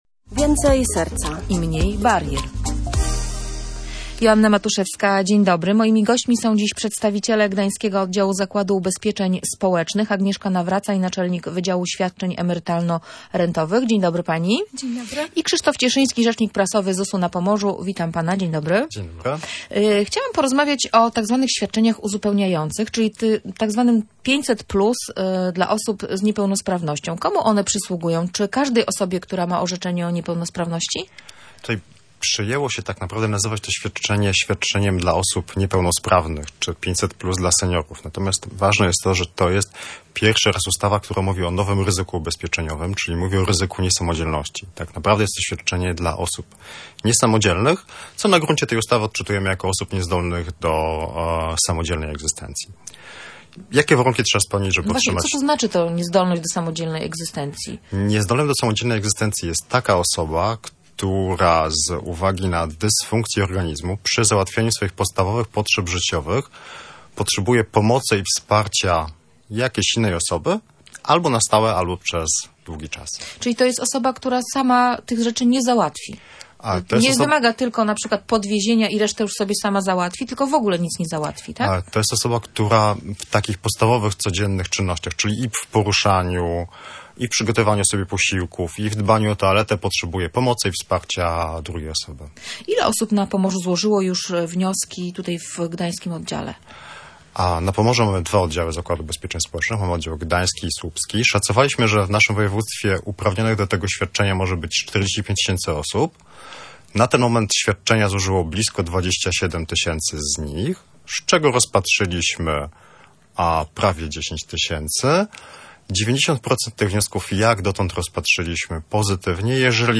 Jak dotąd 90 procent spraw rozpatrzono pozytywnie – mówili w Radiu Gdańsk przedstawiciele pomorskiego ZUS.